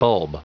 Prononciation du mot bulb en anglais (fichier audio)
Prononciation du mot : bulb